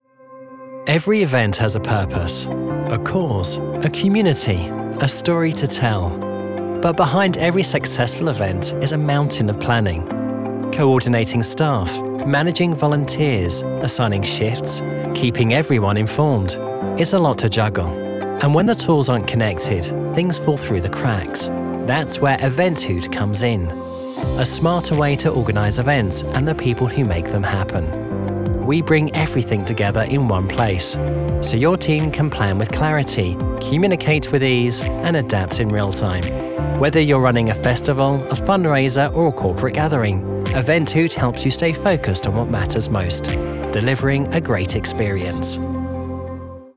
Corporate - Conversational
Gender: Male
Description of voice: I have a neutral British accent. My voice has a fresh, clear, measured and self-assured tone. Friendly, but authoritative if needed!
Home Recording Studio
Microphones: Neumann TLM 102